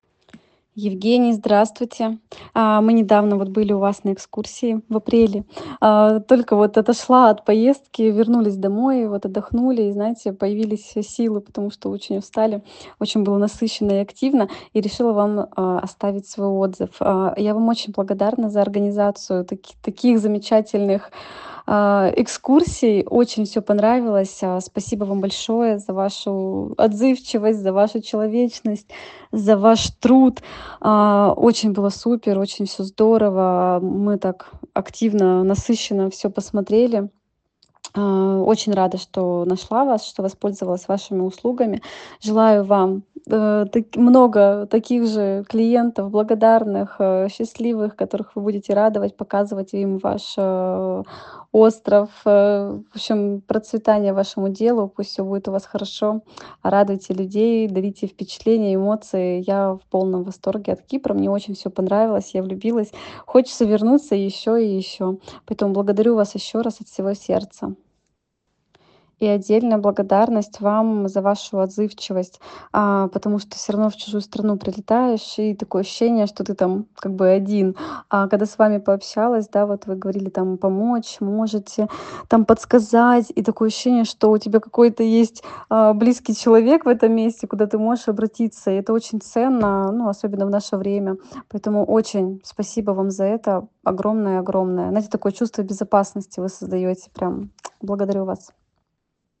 Аудио-отзыв